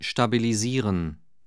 Aussprache:
🔉[ʃtabiliˈziːrən]